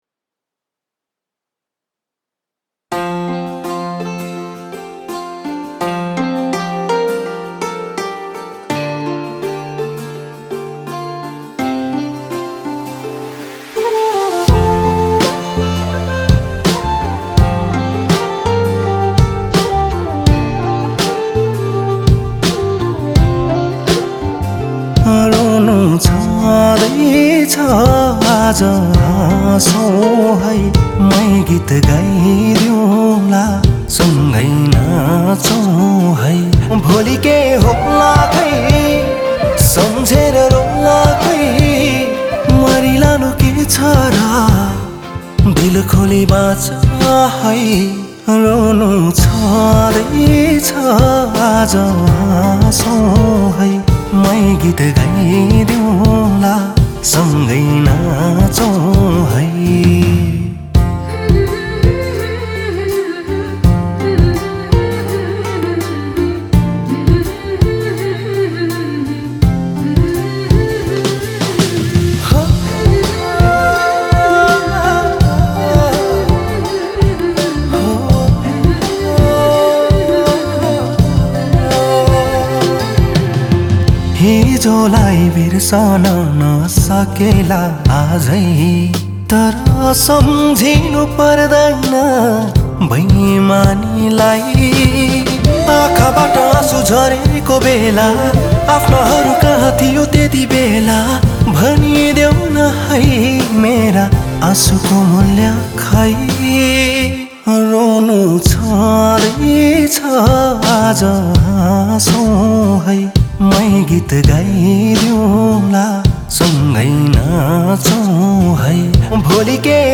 Nepali Romantic Song